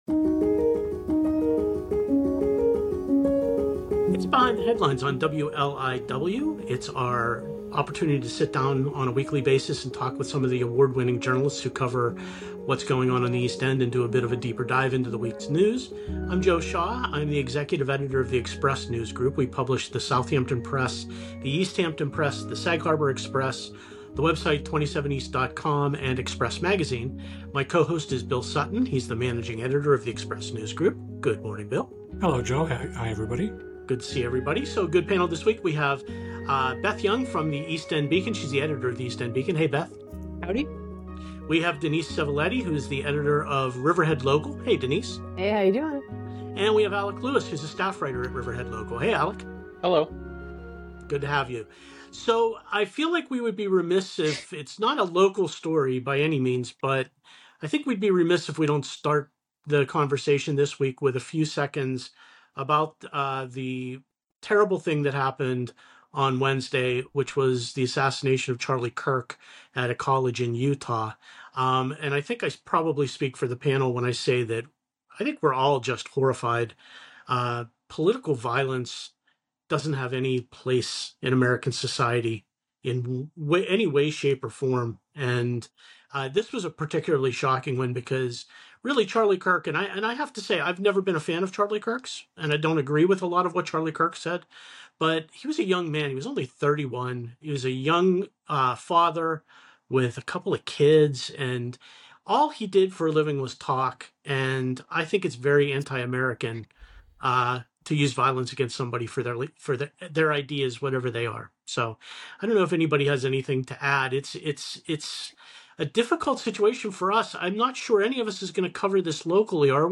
In this episode of ‘Behind the Headlines,’ the panel reflects on the recent assassination of political commentator Charlie Kirk, highlighting the dangers of political violence though expressing disagreement with his views. They also discuss dredging projects in Montauk and Mattituck, Poxabogue Field debates, and the Osprey and Bald Eagle population recoveries.